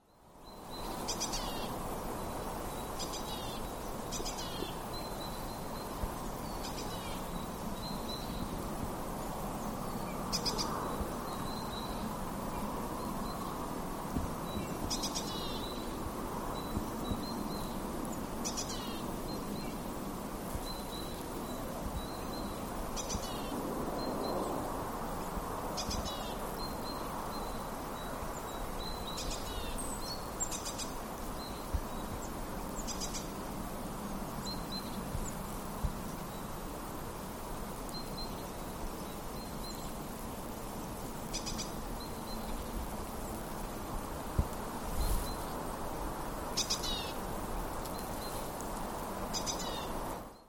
Näin äänteli tiainen, joka lainasi ti-ti-tyy -aiheensa talitiaiselta, äänen sävyn hömötiaiselta, mutta joka saattoi olla myös sinitiainen. Kun lintu ei näyttäytynyt, se jäi arvoitukseksi.